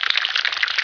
eating.wav